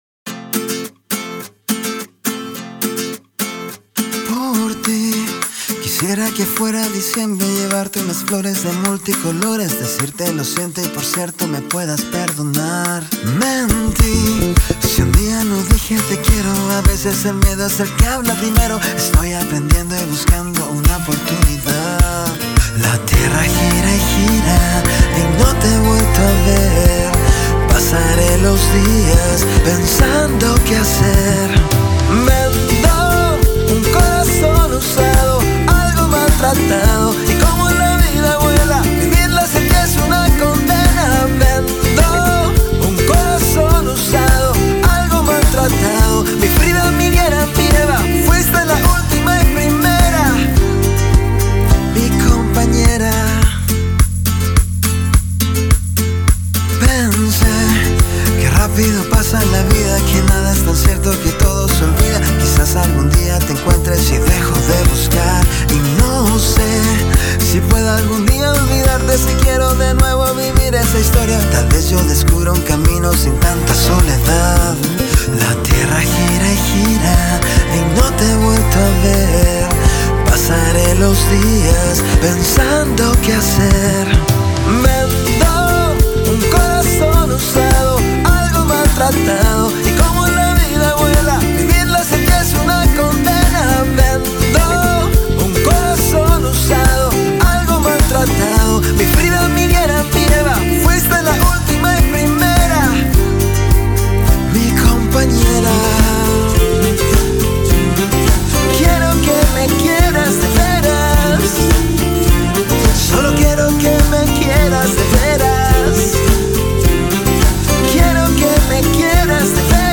Es un tema romántico llevado a la tonalidad fresca y